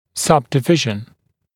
[ˌsʌbdɪ’vɪʒn][ˌсабди’вижн]подкласс; подразделение